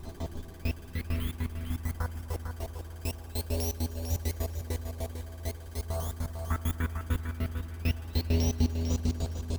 Em (E Minor - 9A) Free sound effects and audio clips
• Clown Texture Glitched 100 Bpm.wav
Clown_Texture_Glitched_100_Bpm__sij.wav